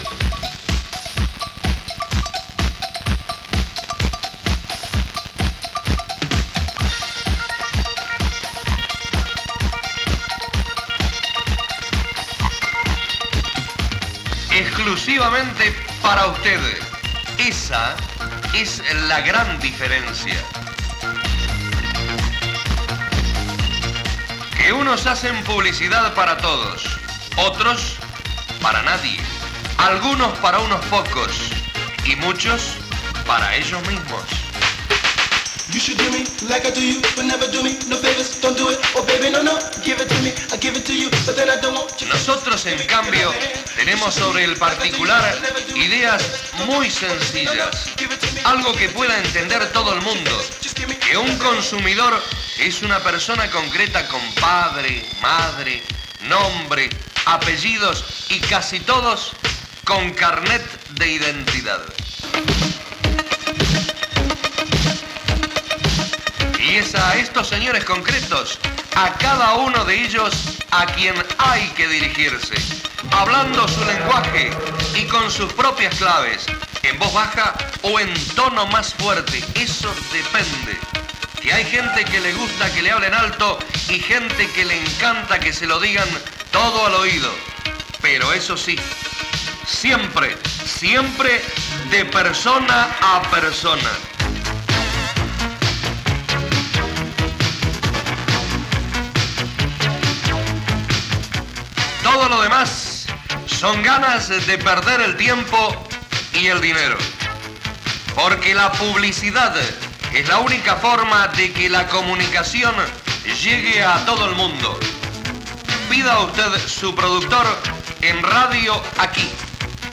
5114c5ca8e3e69baa663a3daf317a1a4cee4bade.mp3 Títol Radio Aquí Emissora Radio Aquí Titularitat Tercer sector Tercer sector Comercial Descripció Anunci per fer publicitat a l'emissora. Gènere radiofònic Publicitat